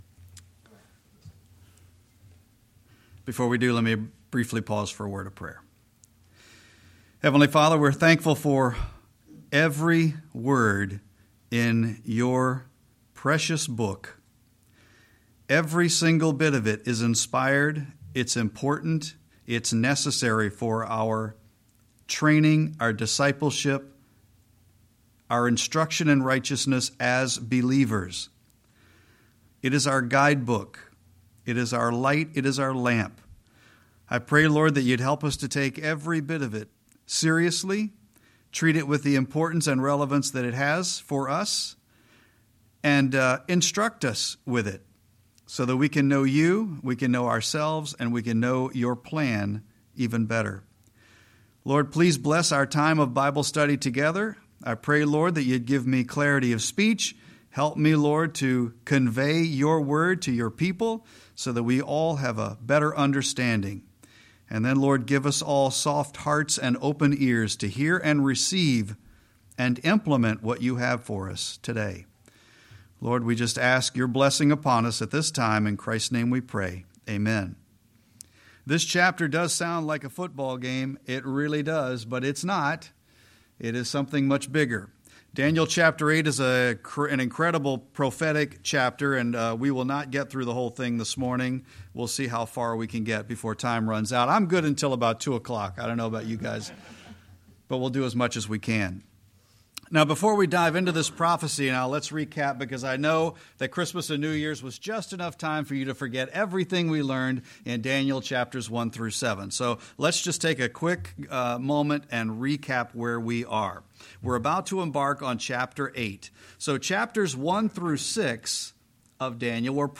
Sermon-1-5-25.mp3